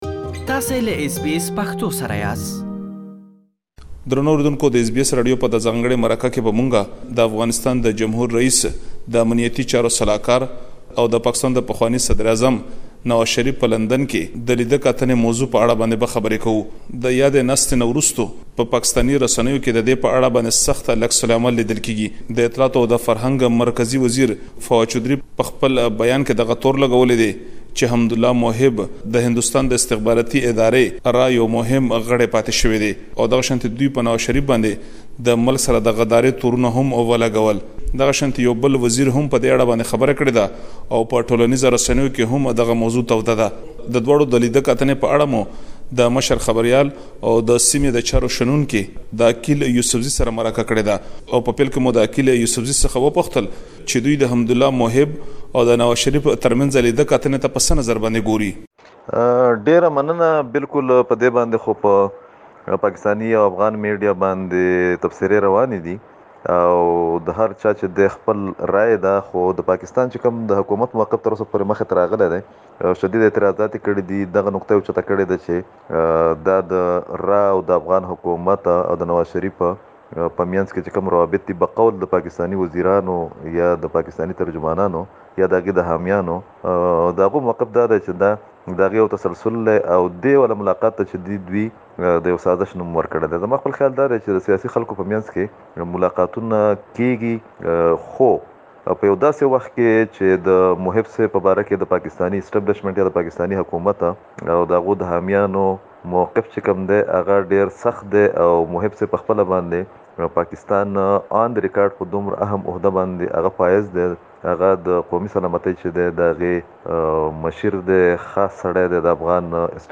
SKIP ADVERTISEMENT تاسو ته مو د يادې ناستې وروسته د پاکستاني رسنيو او چارواکو غبرګون څيړلی چې تاسې دا ټول پدې رپوټ کې اوريدلی شئ.